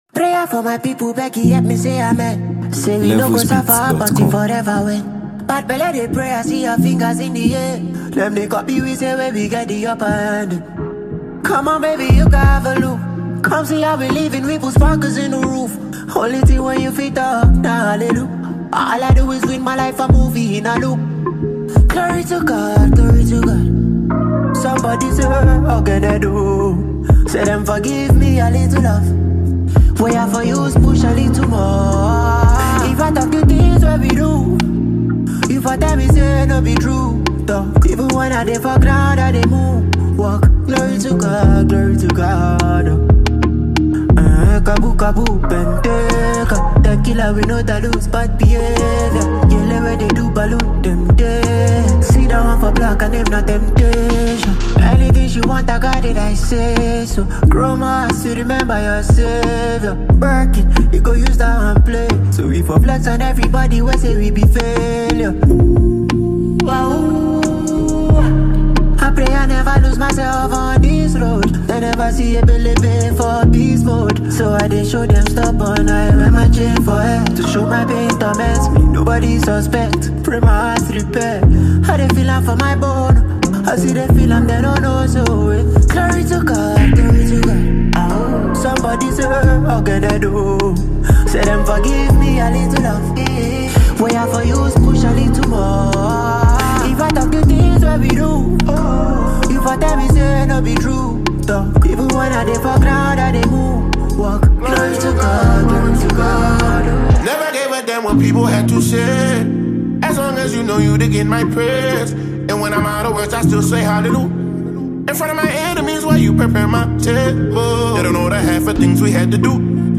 remix
a talented Haitian-American singer and songwriter